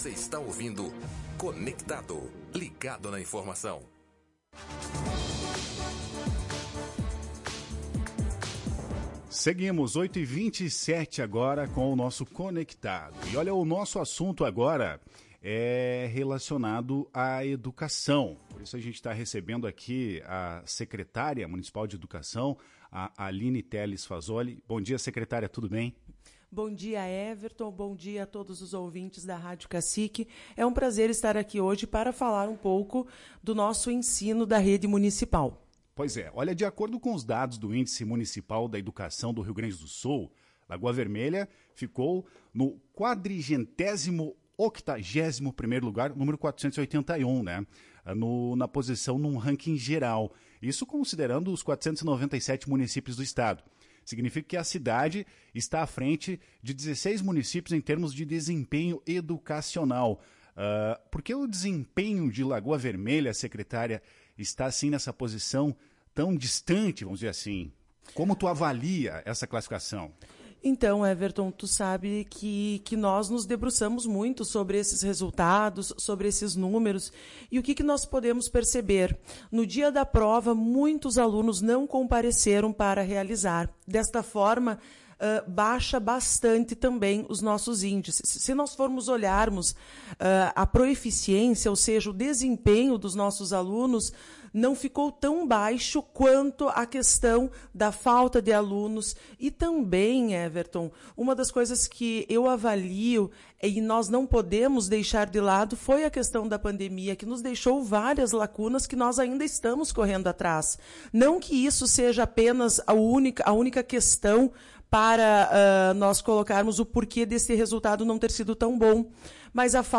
A secretária de Educação do município, Aline Teles Fasoli, abordou essas questões em entrevista à Tua Rádio Cacique.